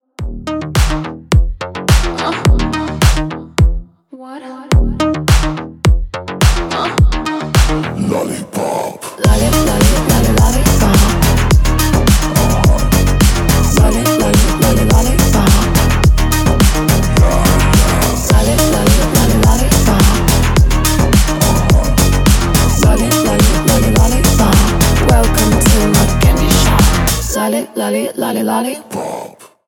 бесплатный рингтон в виде самого яркого фрагмента из песни
Танцевальные
клубные